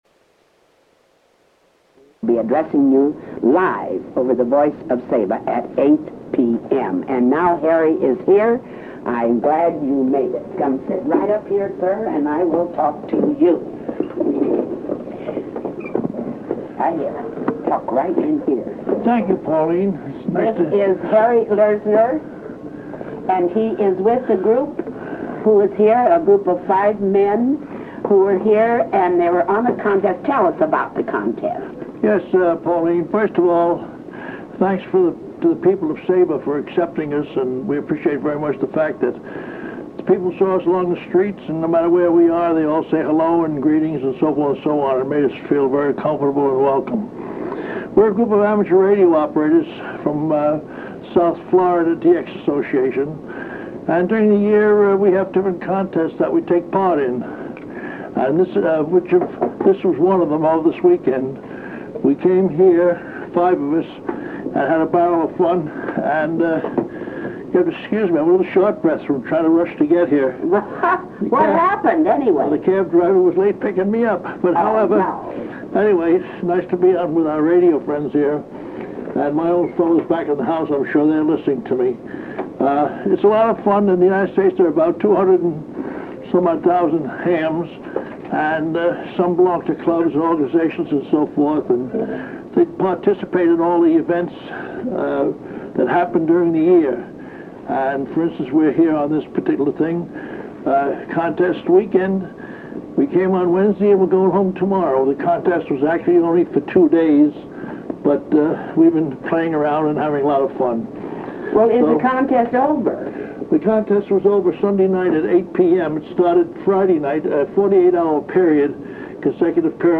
Voice of Saba Interview